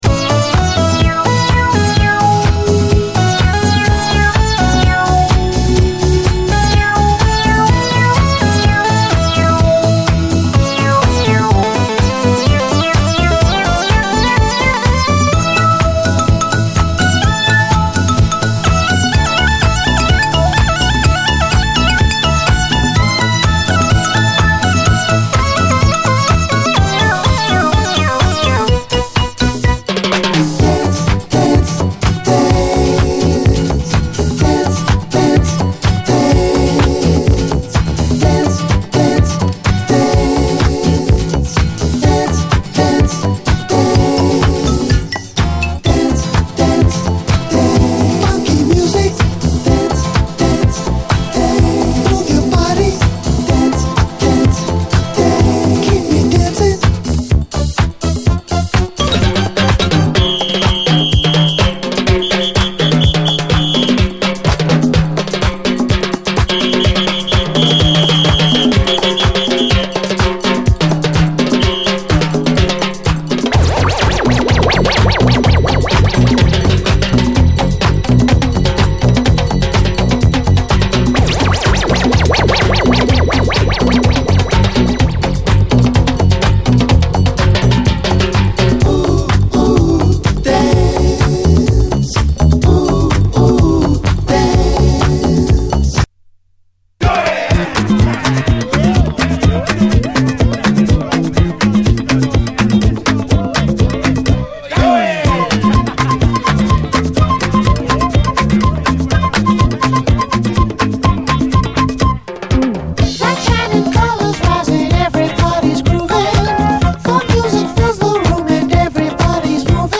SOUL, 70's～ SOUL, DISCO
どちらにもラテンっぽいエッセンスが香るとこがポイントです！